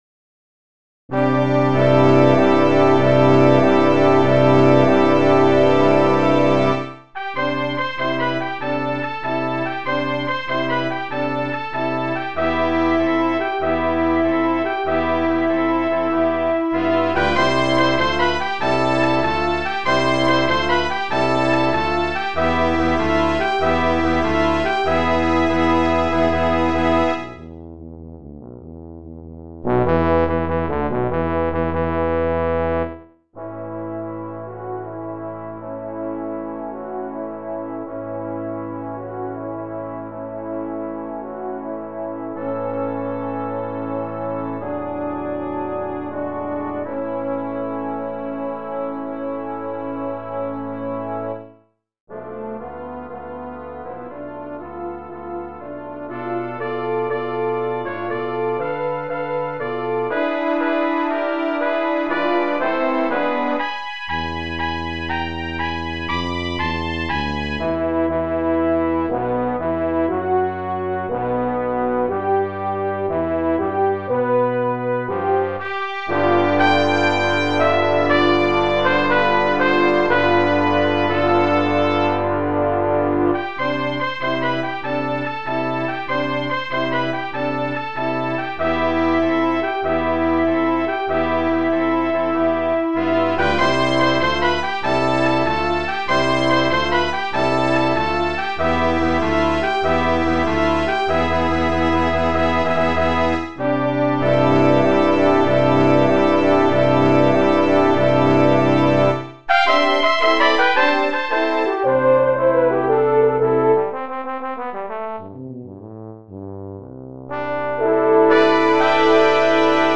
Voicing: Double Brass Quintet